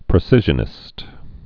(prĭ-sĭzhə-nĭst)